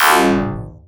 SCIFI_Down_11_mono.wav